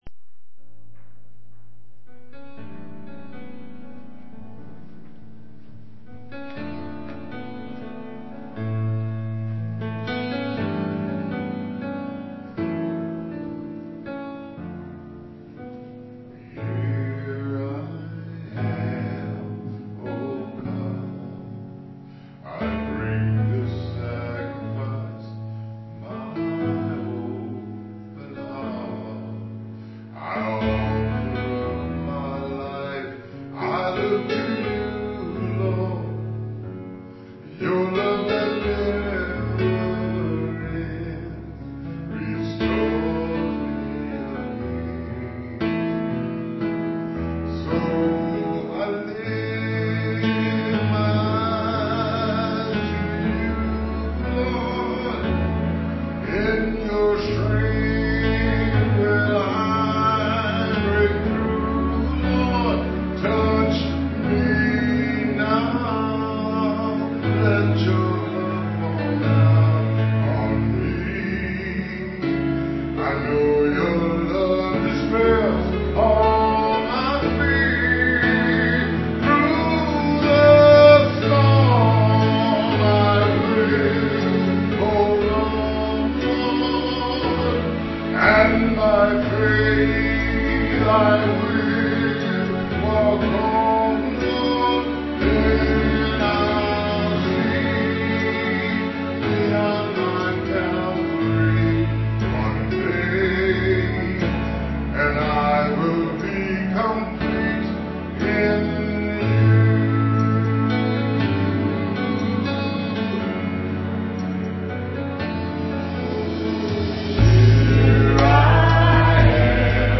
Offertory
digital piano.